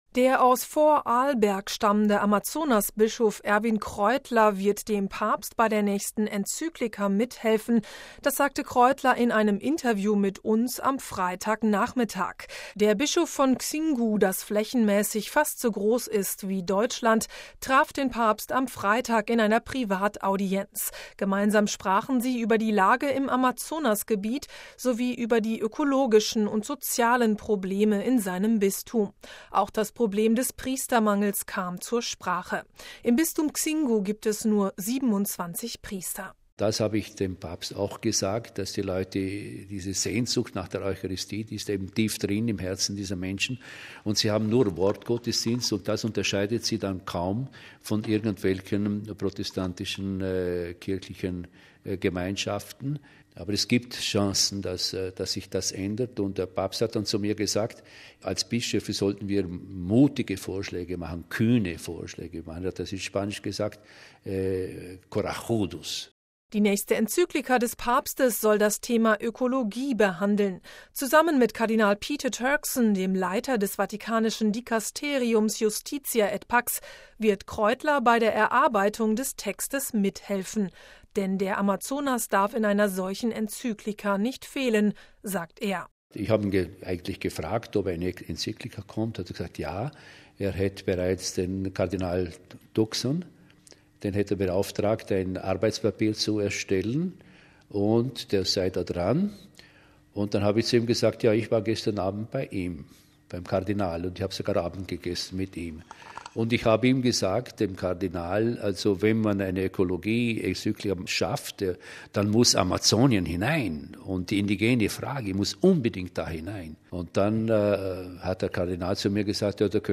Das sagte Kräutler in einem Interview mit Radio Vatikan am Wochenende.